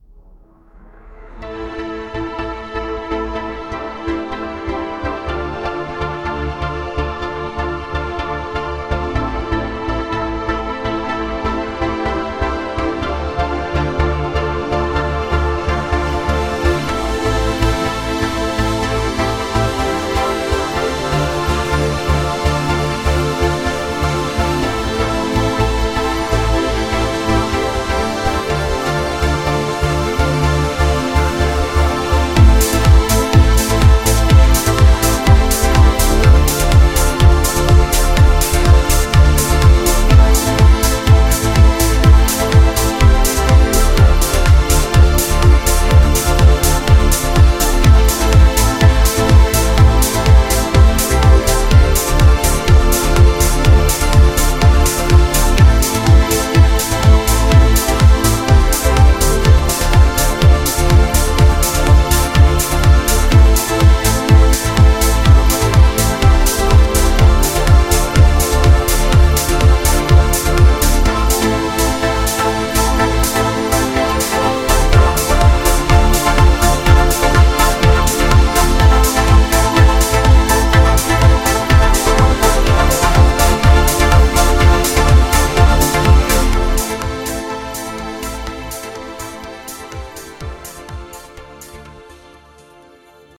Démo d’une production Stable Audio par Stability AI
demo-stabiliti-ai-stable-audio.mp3